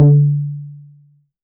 • Subtle Reverb Tome Drum Sample D Key 18.wav
Royality free tom one shot tuned to the D note. Loudest frequency: 166Hz
subtle-reverb-tome-drum-sample-d-key-18-EDD.wav